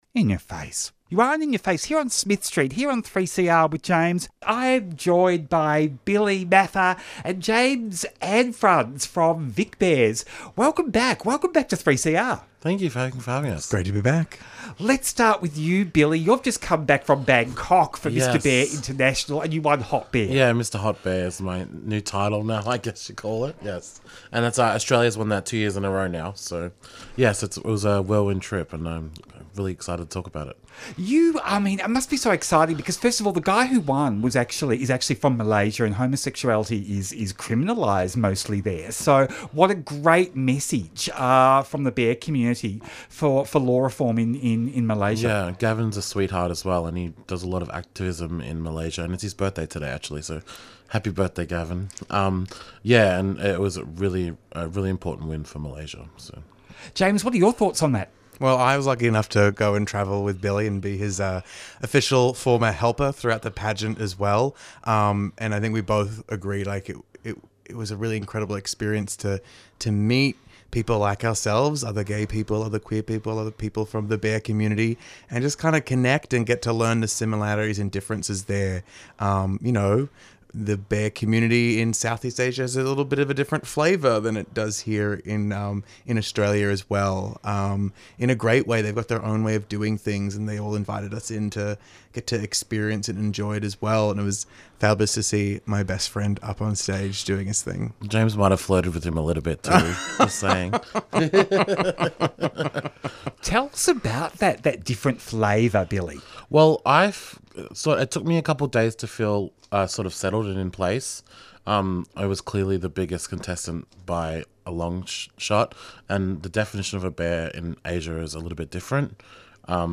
VicBears - Home Tweet In Ya Face Friday 4:00pm to 5:00pm Explores LGBTIQA+ issues with interviews, music and commentary.